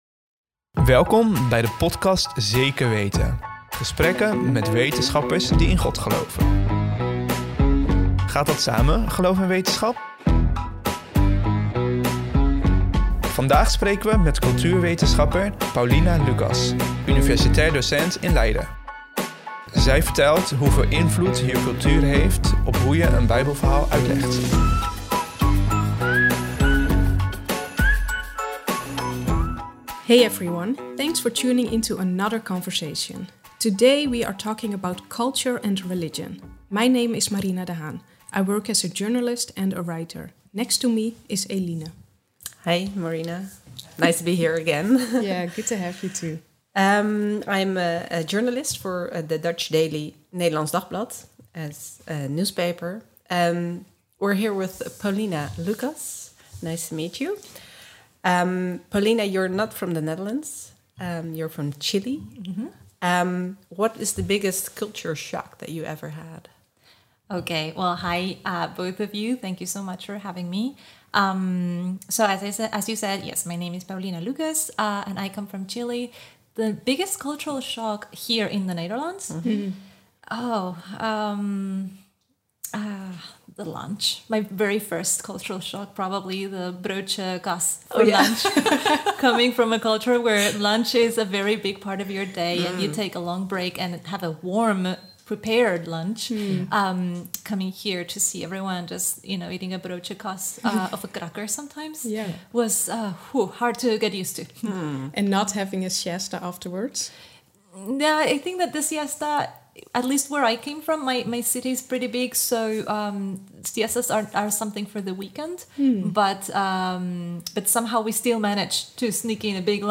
Welkom bij de podcast Zeker Weten, gesprekken met wetenschappers die in God geloven. Gaat dat samen, geloof en wetenschap?